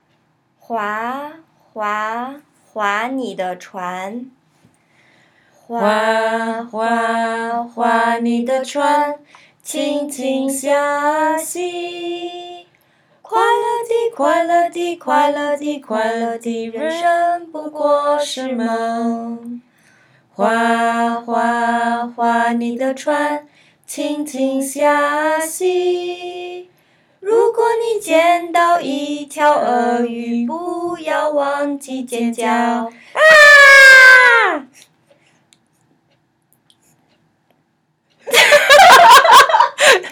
We usually reserve our song audio files for enrolled families only…but this one is a blooper 🙂 We were trying to put together something for Row Your Boat while having a bad case of giggles – and spent 10 minutes laughing before and after it.
row-your-boat-crocodile-blooper.m4a